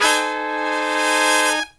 LONG HIT03-R.wav